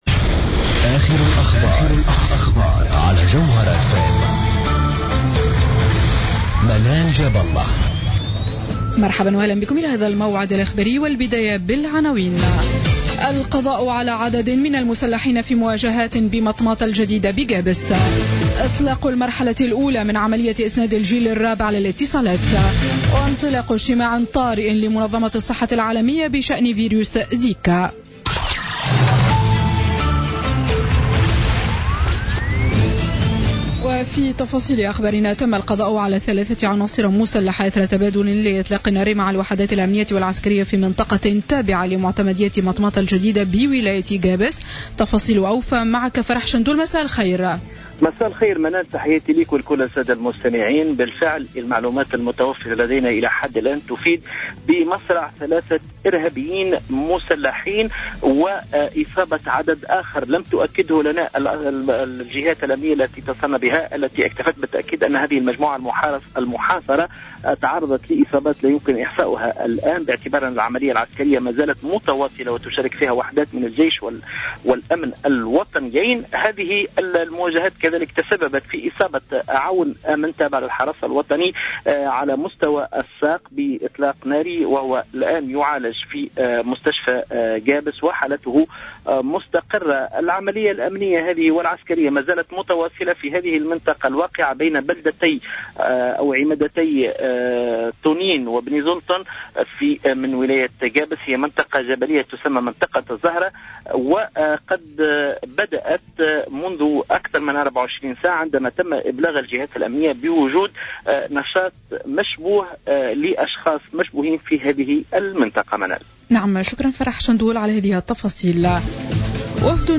Journal Info 19h00 du lundi 01 février 2016